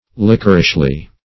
[1913 Webster] -- Lick"er*ish*ly, adv.